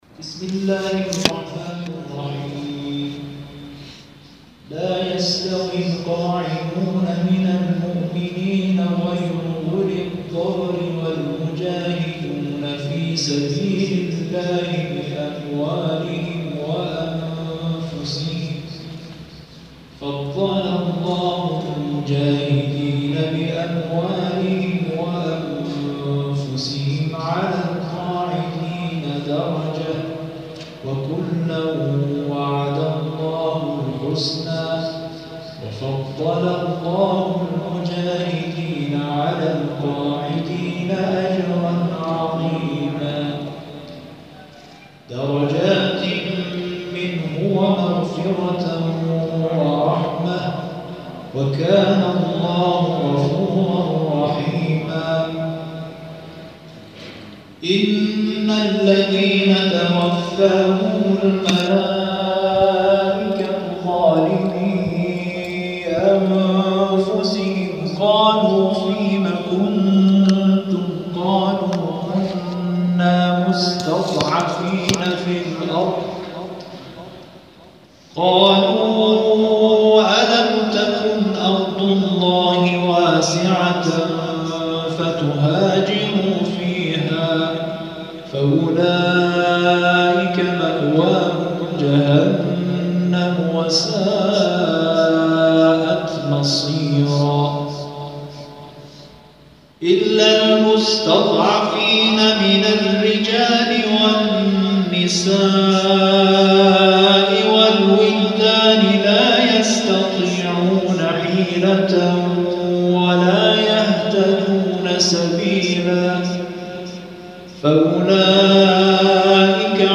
در این جلسه، اسم‌نویسی صورت می‌گیرد و طبق لیست، اعضای جلسه به تلاوت آیات می‌پردازند.
در پایان تلاوت‌های این جلسه ارائه می‌شود.